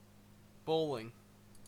Ääntäminen
Synonyymit tenpin bowling Ääntäminen CA : IPA : [ˈboʊ.lɪŋ] UK : IPA : /ˈbəʊ.lɪŋ/ IPA : /ˈbɔʊlɪŋ/ US : IPA : /ˈboʊliŋ/ Haettu sana löytyi näillä lähdekielillä: englanti Käännös Substantiivit 1.